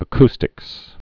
(ə-kstĭks)